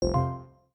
Dun Don Alert.wav